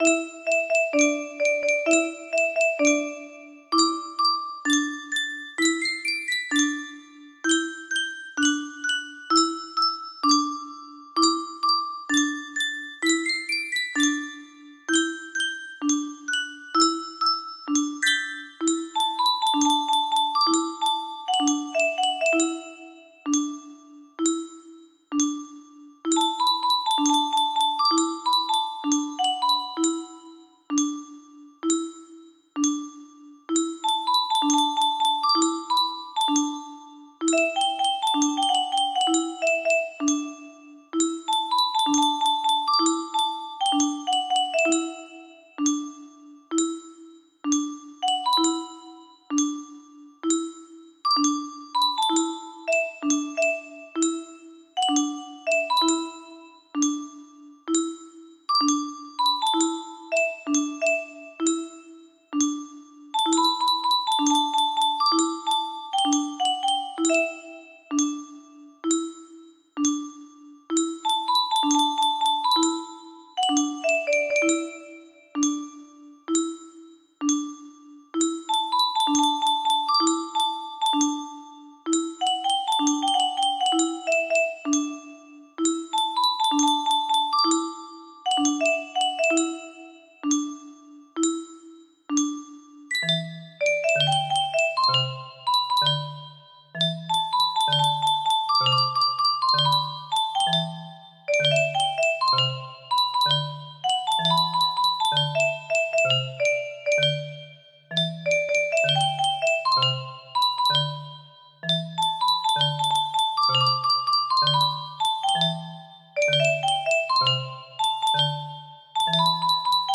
Full range 60
Imported from MIDI File